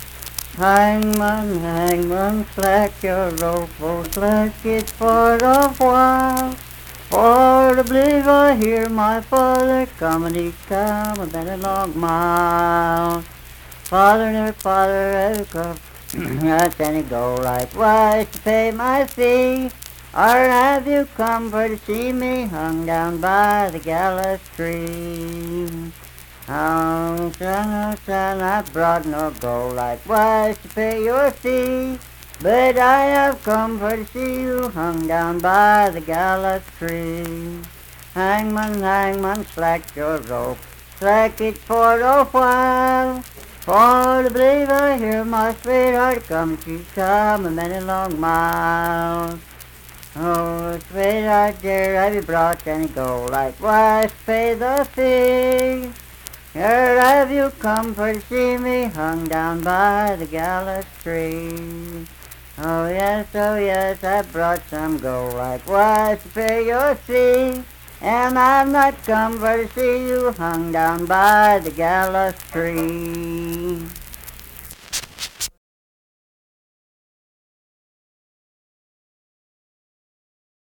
Unaccompanied vocal music performance
Verse-refrain 6(4w/R).
Voice (sung)
Clay County (W. Va.)